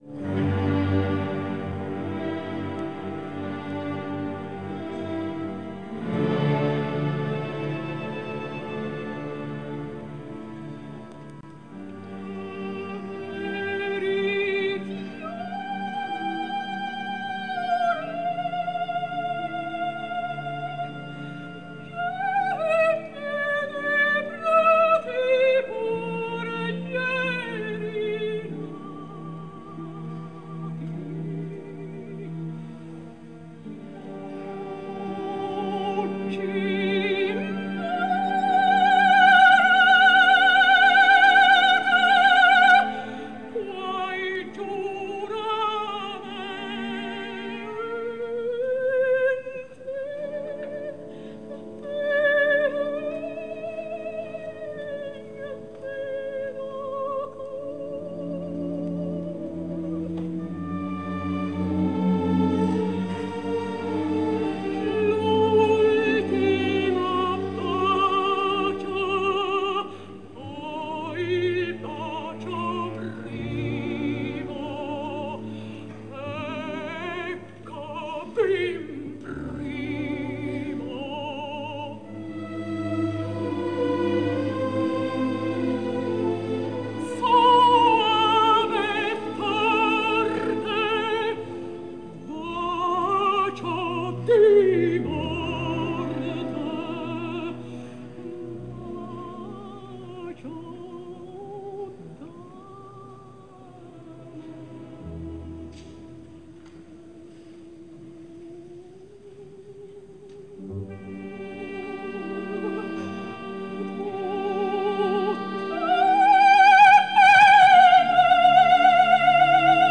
Жанр: Opera
итальянская оперная певица, сопрано.